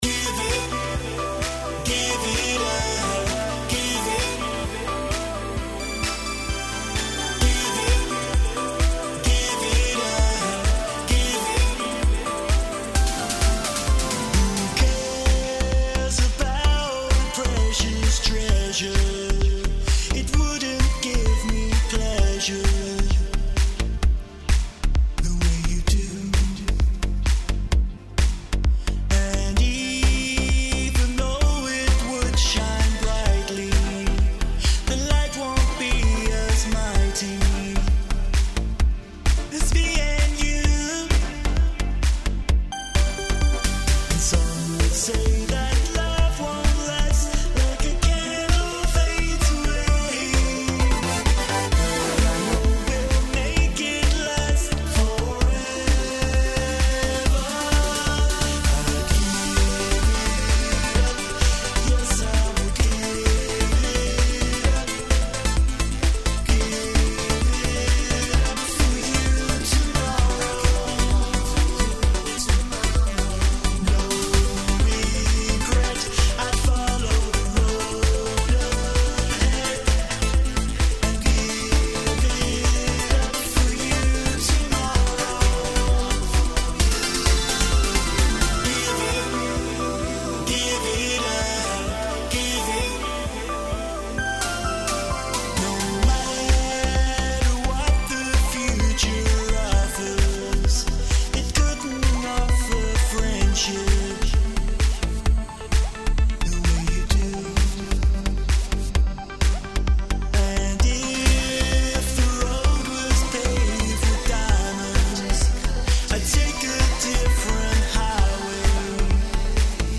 • Radio Ready Mix – a punchy, radio-ready cut